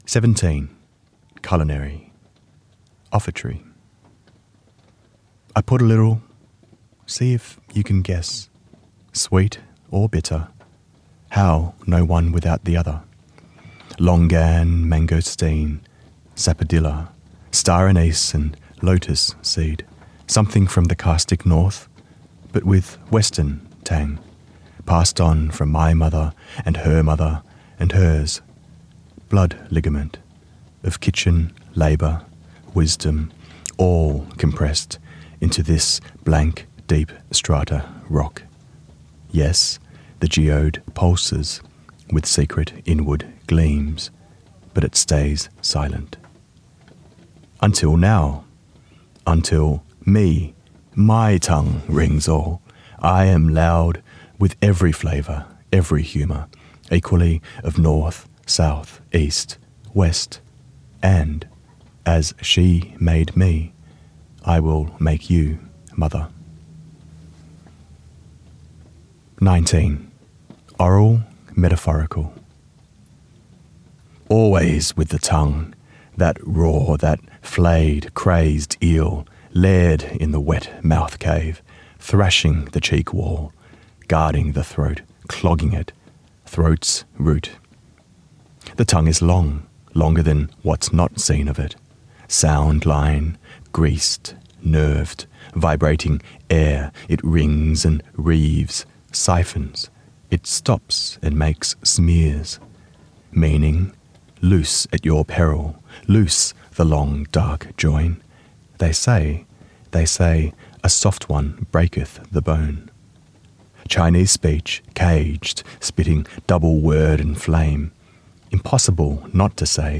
They are punchy, wry and solemn - meditations on life and relationships. Here Nam Le reads two of them beautifully.
Recorded at Byron Writers Festival 2024